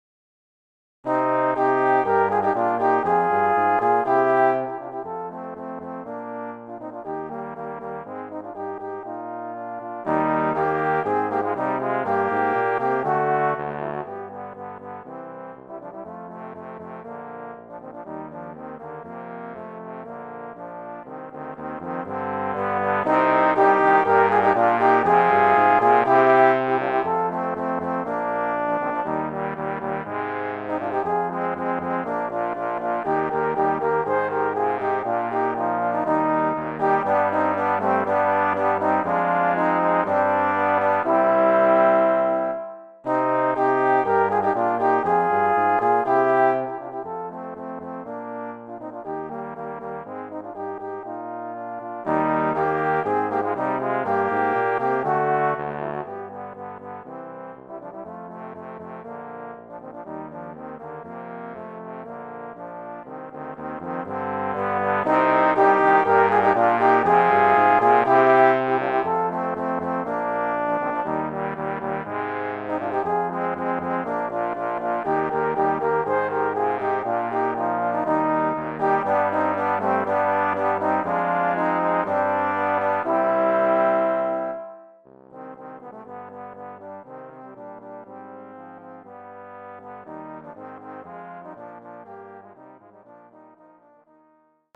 Voicing: Trombone Quartet